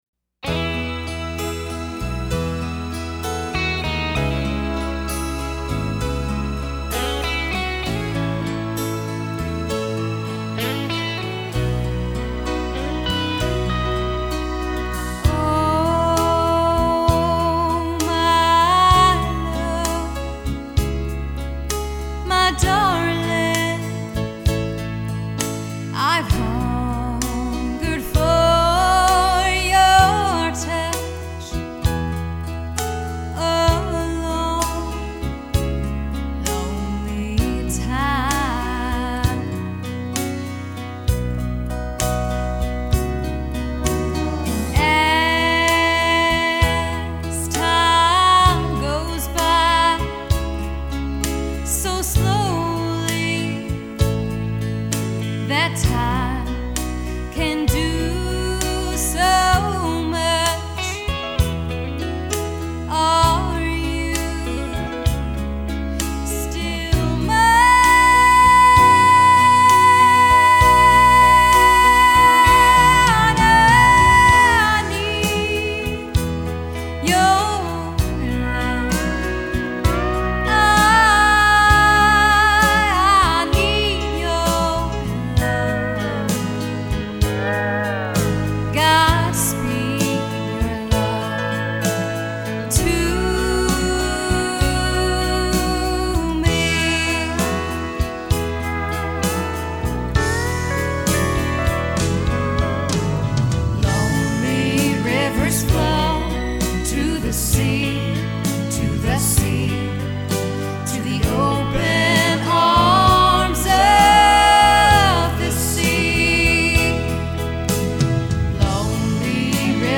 Listen to the band and me below.